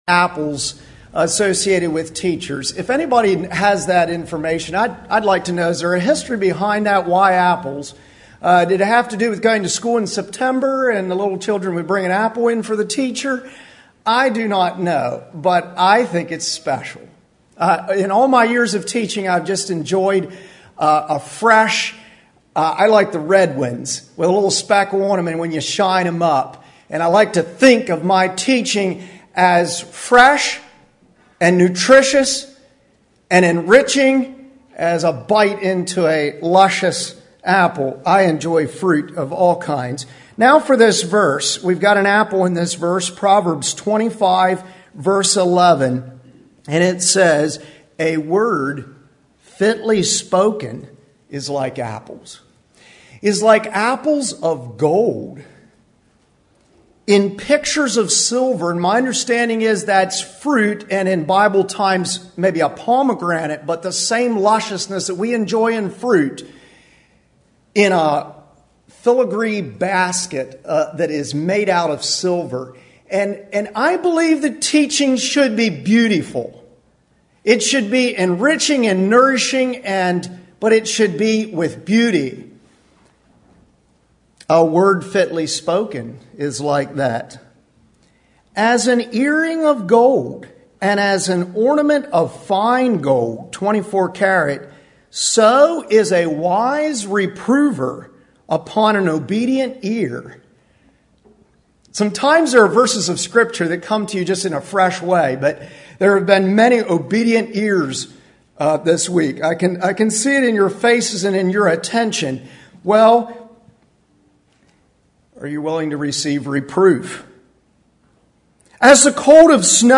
Home » Lectures » Golden Rules for Teachers
Western Fellowship Teachers Institute 2023